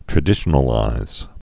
(trə-dĭshə-nə-līz)